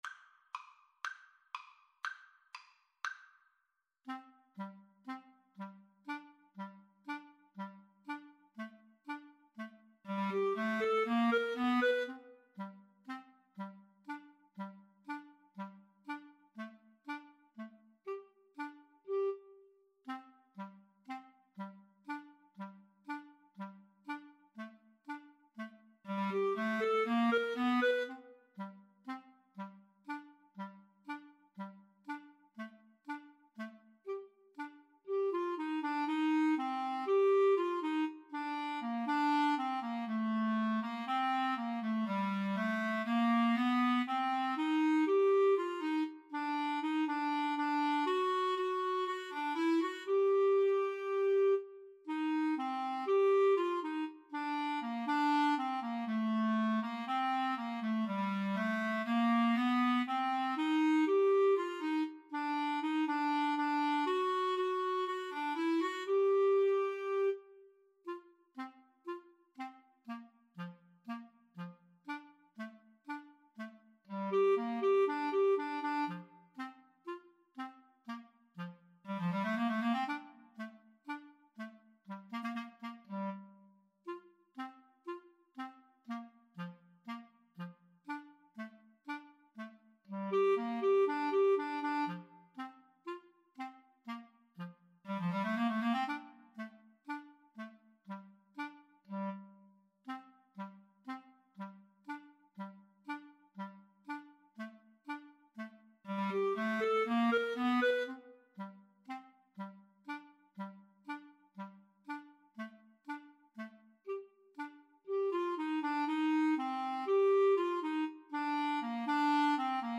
Free Sheet music for Clarinet Duet
G minor (Sounding Pitch) A minor (Clarinet in Bb) (View more G minor Music for Clarinet Duet )
Fast Two in a Bar =c.120
Traditional (View more Traditional Clarinet Duet Music)